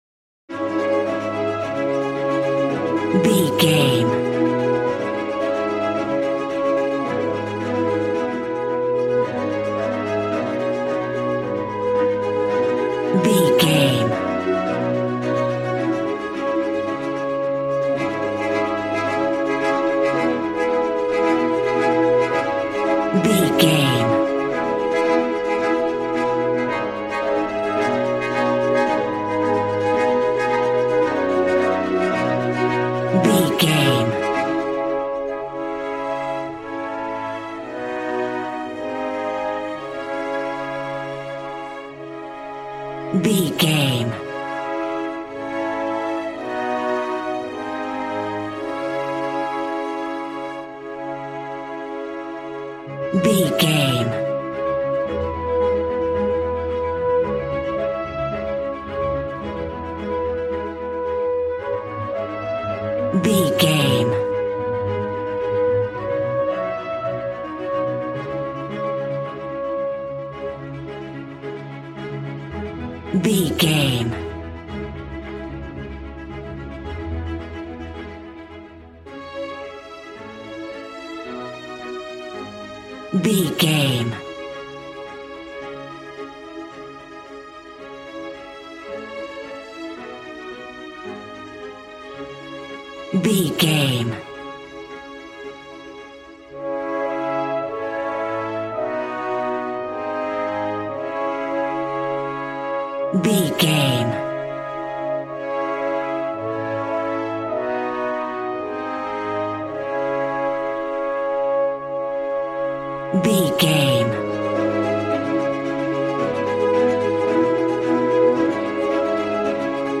A classical music mood from the orchestra.
Regal and romantic, a classy piece of classical music.
Aeolian/Minor
D♭
cello
violin
strings